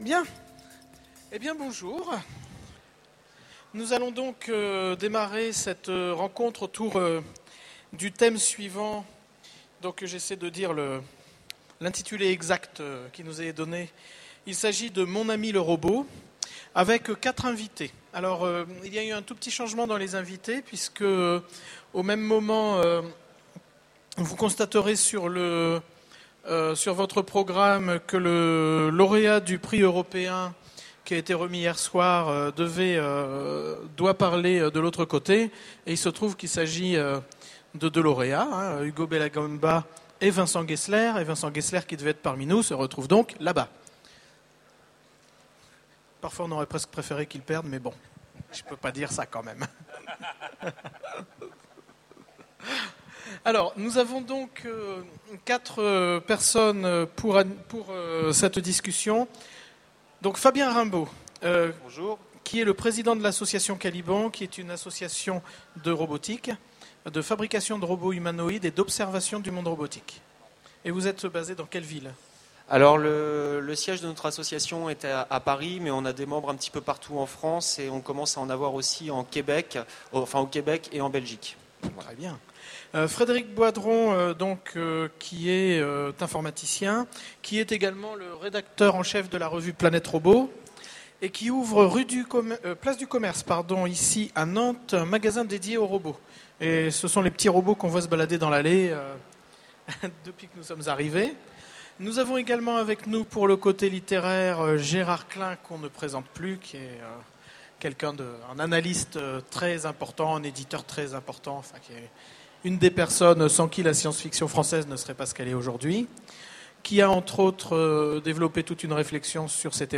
Utopiales 2010 : Conférence Mon ami le Robot
Voici l'enregistrement de la conférence " Mon ami le Robot " aux Utopiales 2010. Notre vie quotidienne nous amène de plus en plus à interagir avec des machines intelligentes (bornes interactives, téléphones ou ordinateurs). À quand le robot-enfant, les robots animaux ou d’assistance à domicile ?